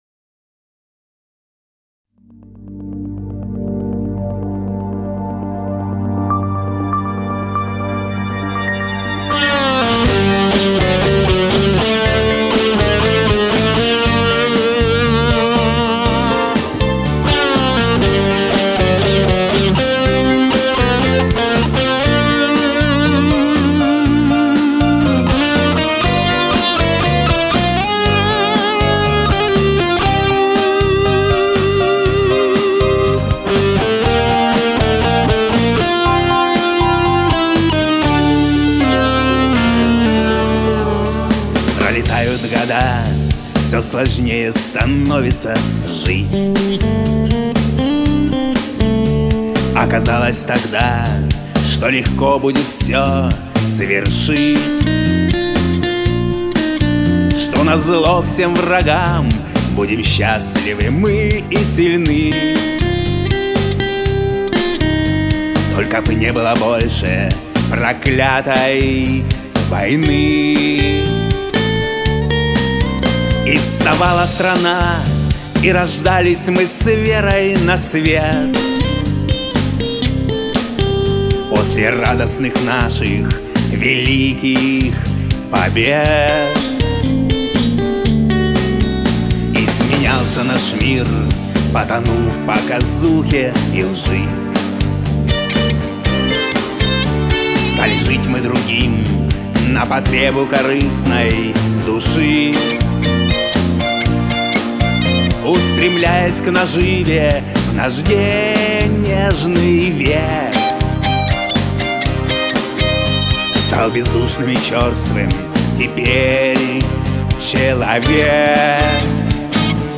• Жанр: Бардрок